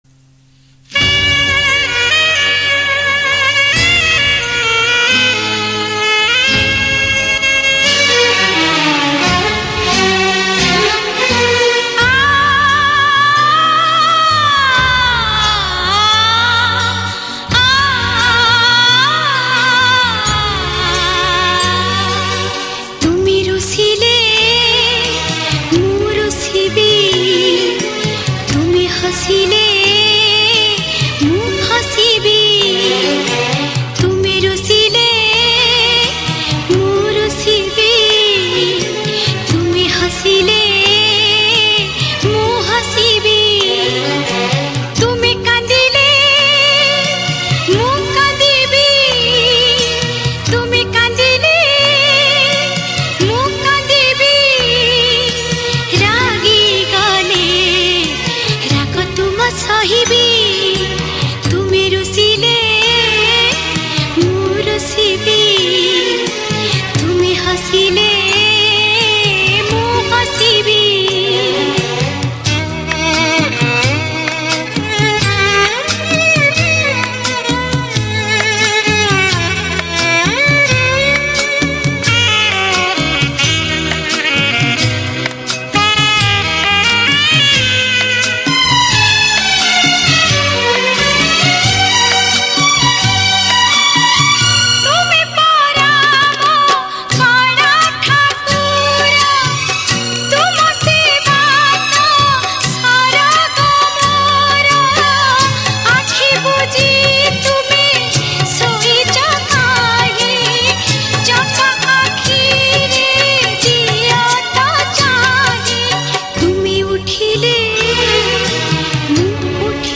Female Version